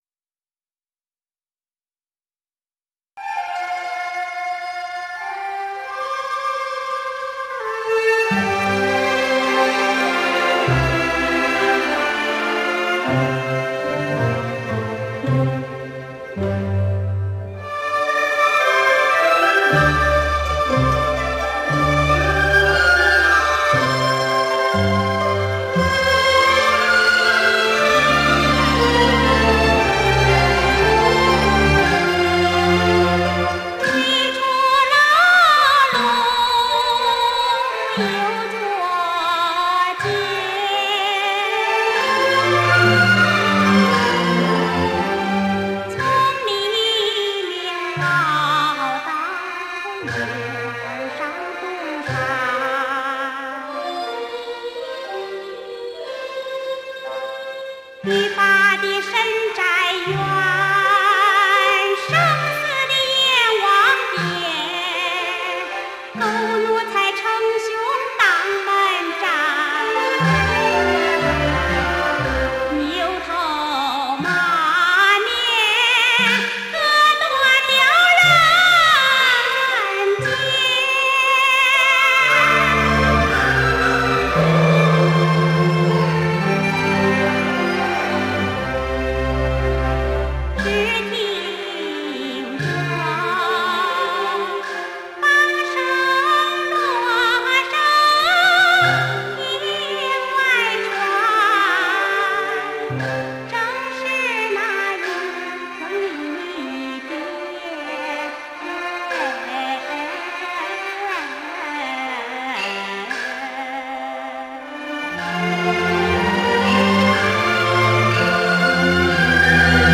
类别：九场歌剧
但非常有趣的是这部以浙江台州为背景的歌剧作品，它的音乐居然完全采用了河南地区的民歌元素！
她的水晶般透明的嗓音、完全的天然的唱法，根本没有经过科班的雕琢。
真可惜了那时的录音技术！
不过这录音也算差强人意了。